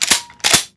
sg-deploy.wav